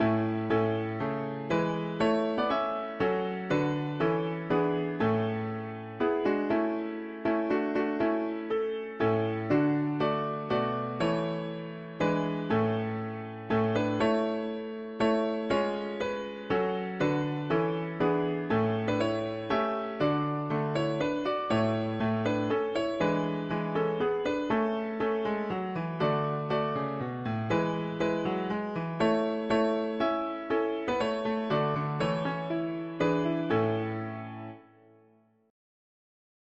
A Alto volume adjust.
T Tenor volume adjust.
B Bass volume adjust.
Key: A major